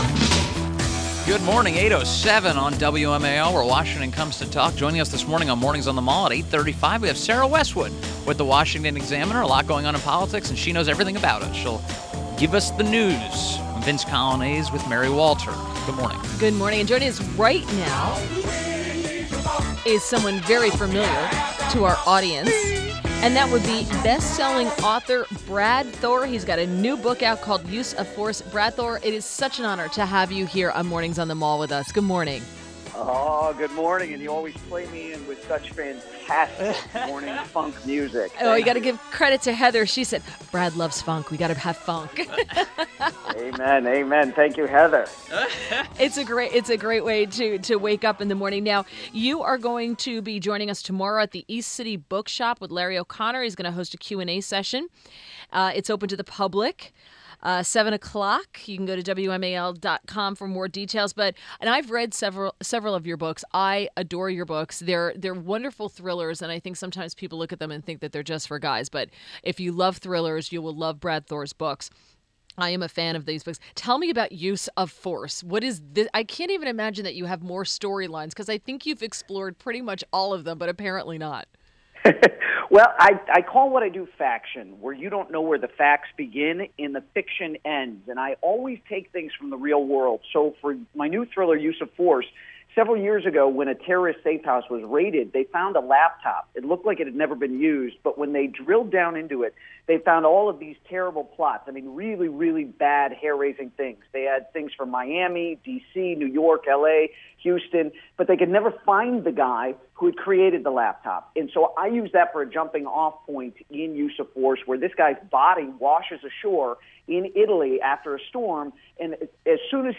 WMAL Interview - BRAD THOR 07.13.17